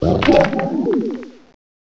sovereignx/sound/direct_sound_samples/cries/houndstone.aif at 2f4dc1996ca5afdc9a8581b47a81b8aed510c3a8